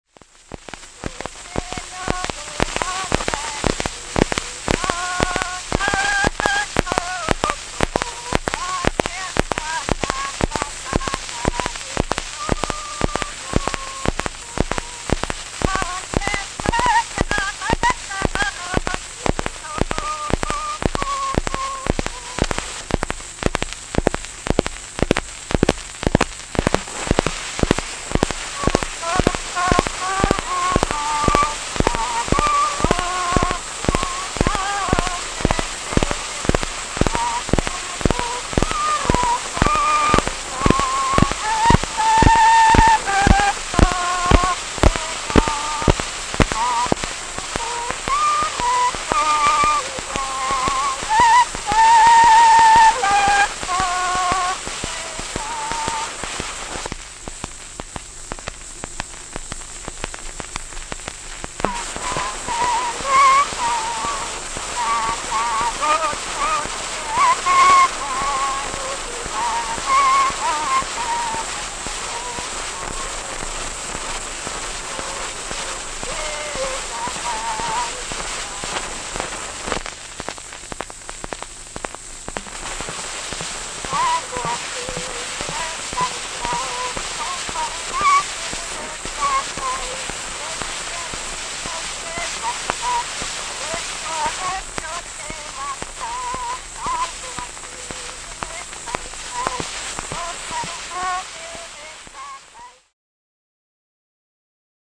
Latviešu valodas dialektu skaņu pieraksti : vaska ruļļi
Fonogrāfa ieraksti
Latviešu valodas dialekti